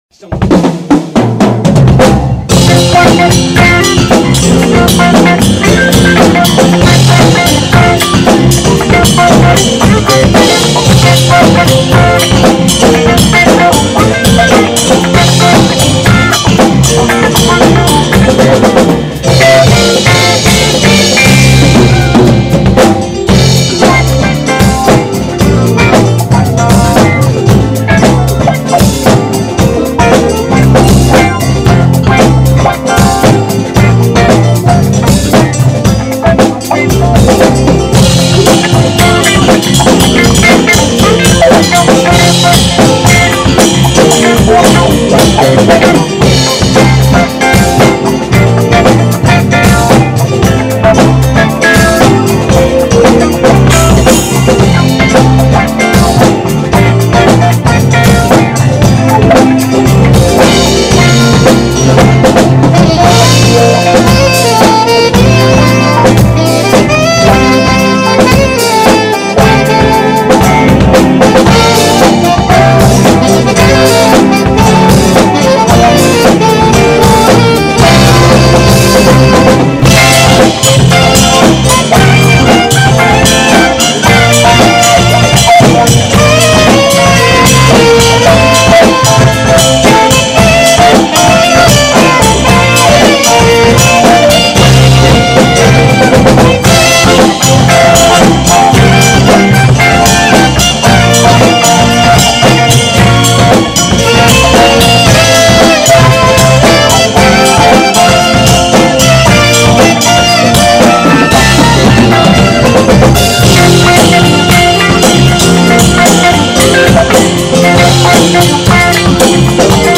정말 부드럽게 잘부시네요 나중에 합주한번 했음 좋겠네요.ㅎ